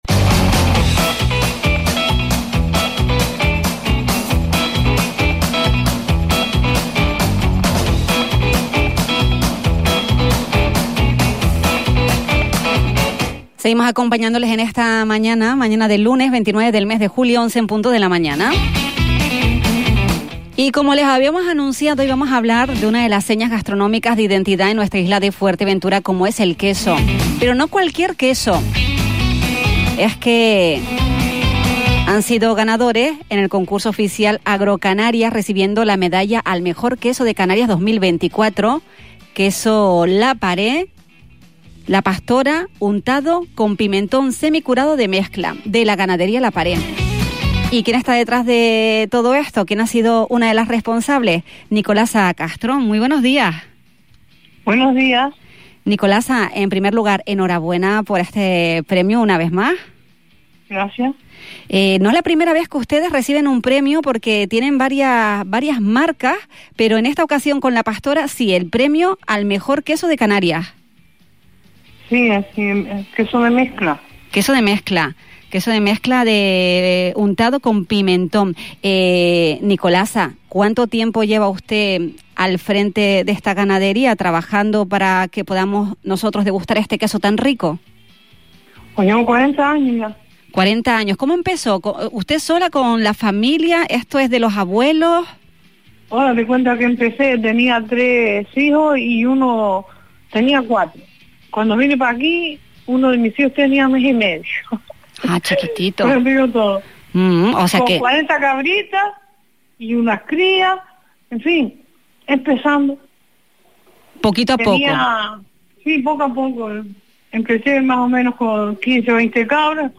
En una entrevista durante el programa «La Mañana Xtra» de Radio Insular